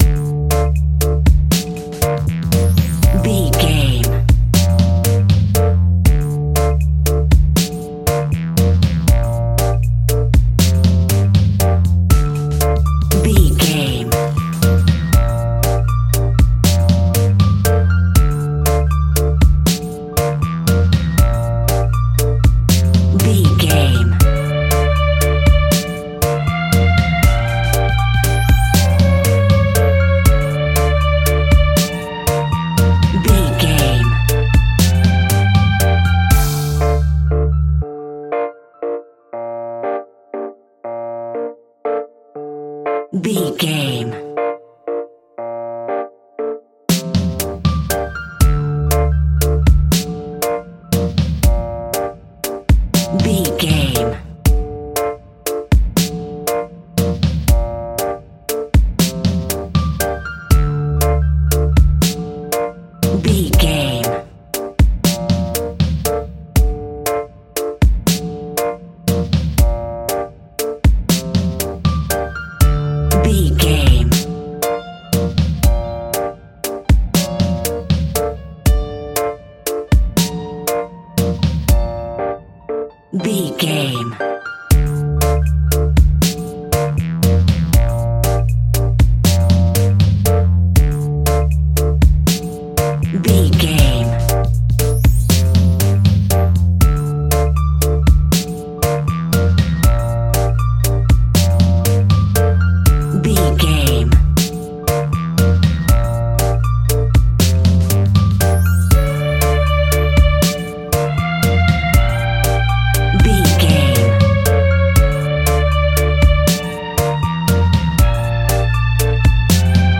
In-crescendo
Aeolian/Minor
scary
tension
ominous
dark
haunting
eerie
strings
brass
percussion
violin
cello
double bass
horror
cymbals
gongs
viola
french horn trumpet
taiko drums
timpani